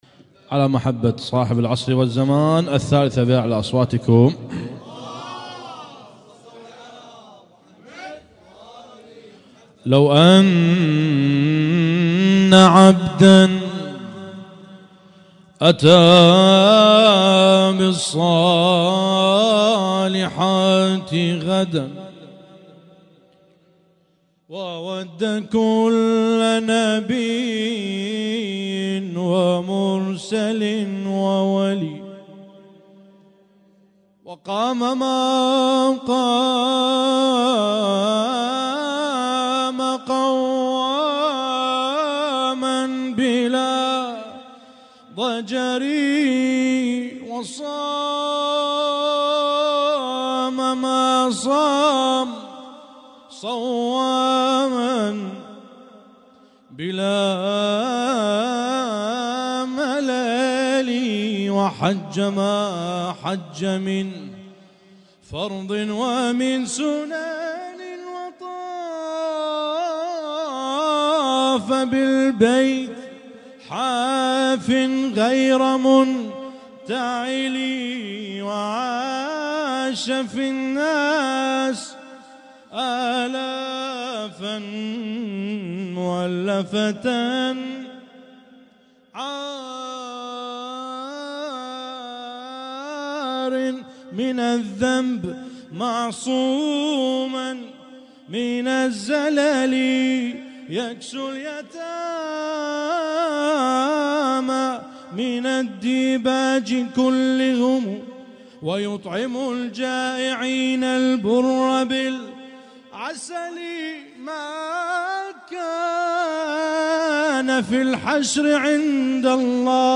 Husainyt Alnoor Rumaithiya Kuwait
اسم النشيد:: مولد الأمام المهدي عجل الله فرجه الشريف
الرادود